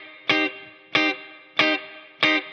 DD_TeleChop_95-Cmin.wav